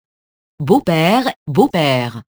beau-père [bopɛr] nom masculin (pluriel beaux-pères)